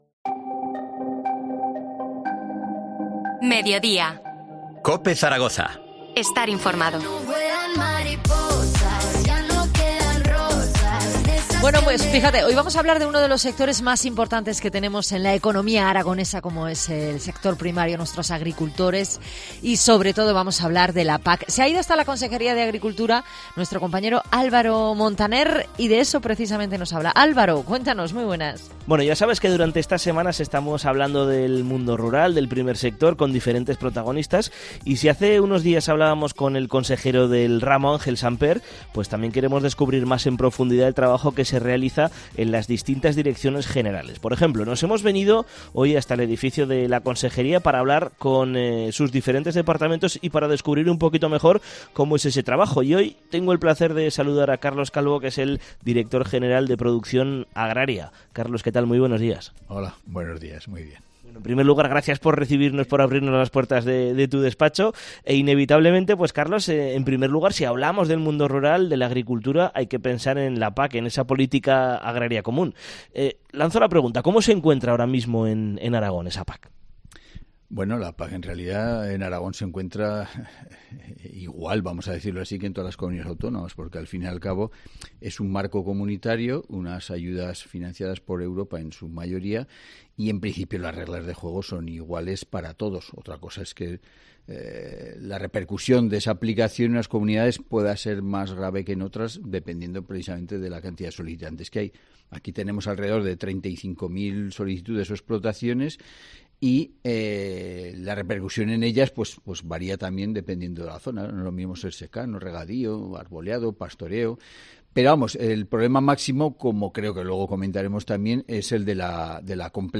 Entrevista a Carlos Calvo, Director General de Producción Agrícola